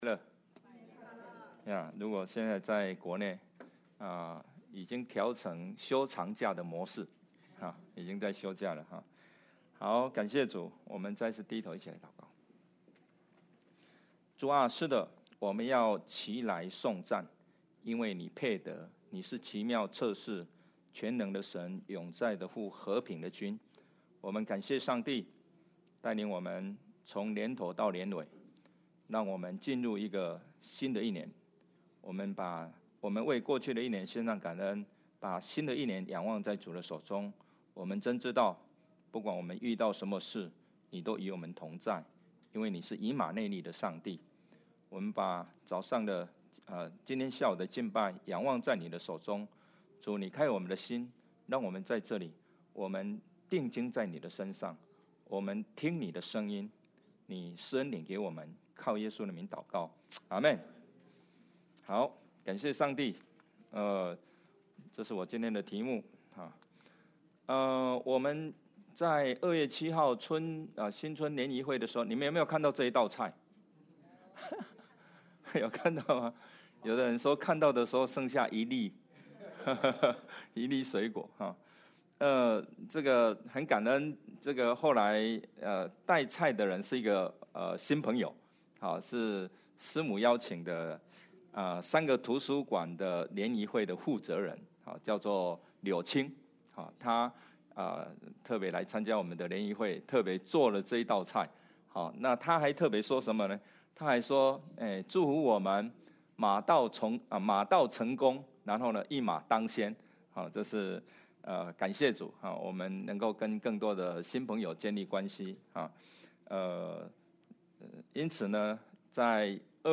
February 15, 2026 天国的奥秘 Passage: Matthew 13:10–35 Service Type: 主日证道 Download Files Notes « 榮神益人 你在這裡作什麼？